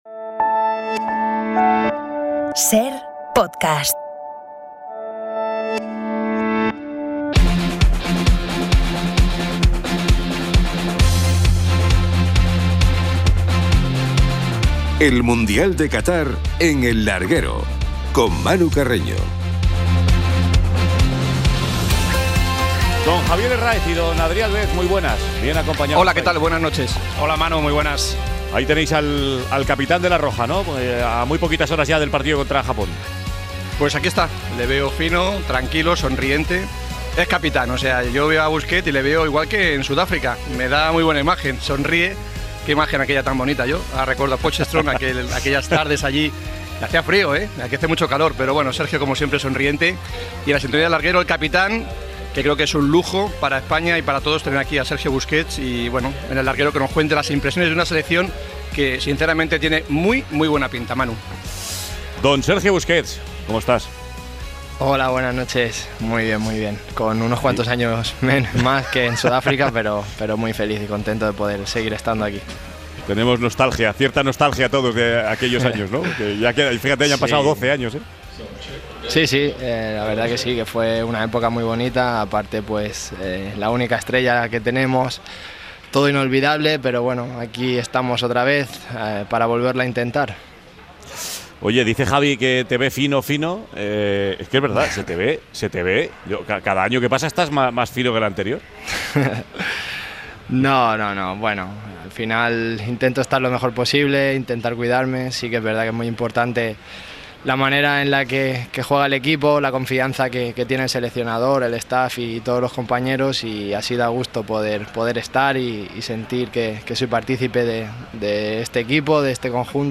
Entrevista a Sergio Busquets y el análisis de Manuel Jabois